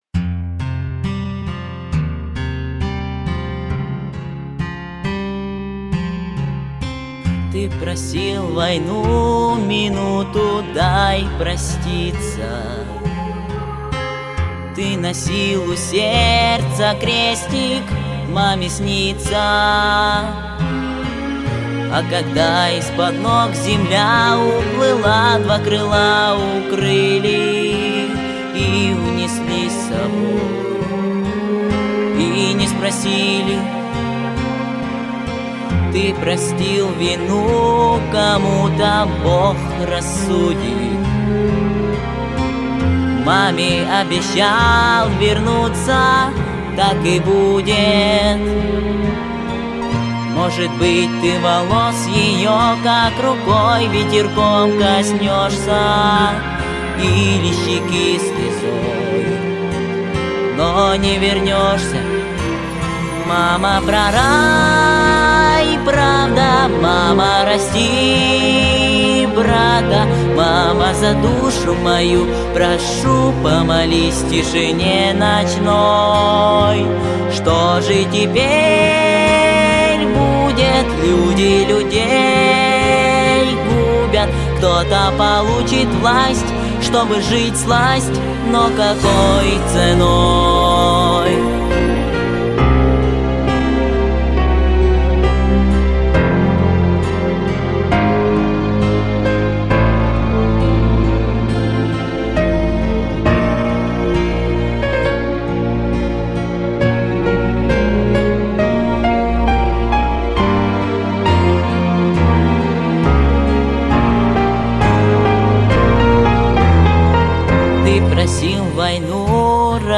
• Категория: Детские песни
грусть, военные песни
Детская эстрадная вокальная студия.